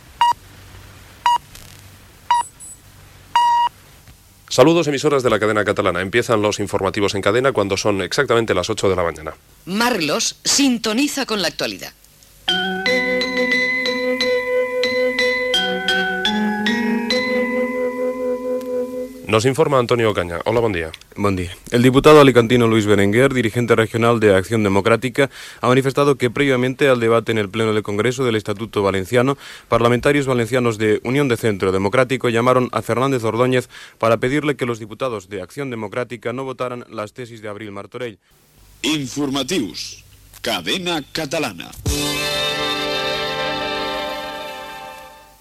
Indicatiu de l'emissora, careta del butlletí, notícia i sortida del butlletí.
Informatiu